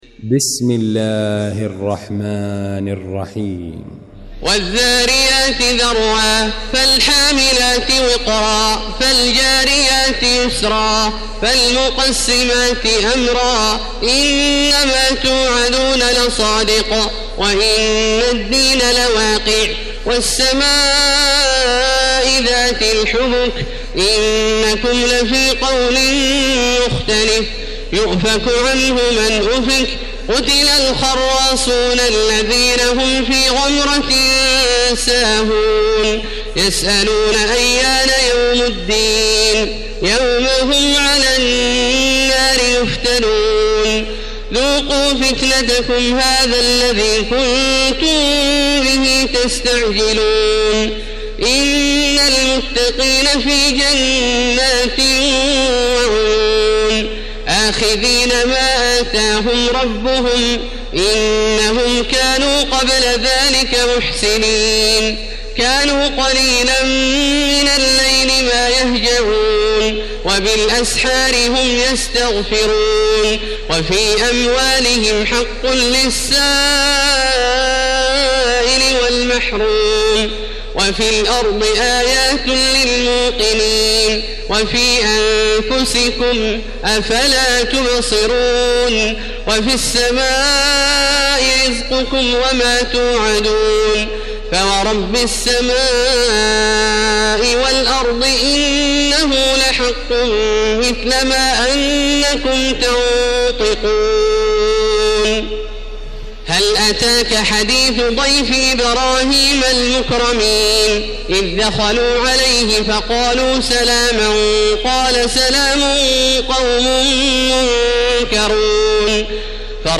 المكان: المسجد الحرام الشيخ: فضيلة الشيخ عبدالله الجهني فضيلة الشيخ عبدالله الجهني الذاريات